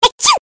Ses Efektleri